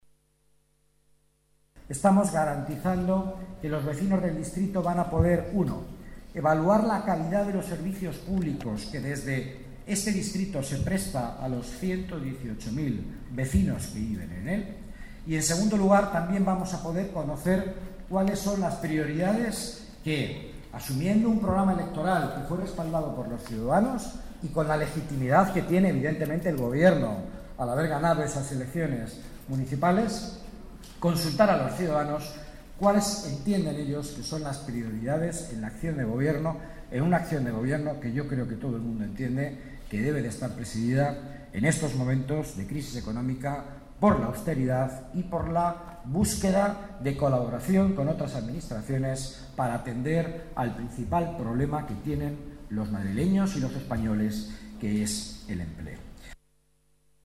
Nueva ventana:Declaraciones del delegado de Economía y Participación Ciudadana, Miguel Ángel Villanueva: Moncloa Participa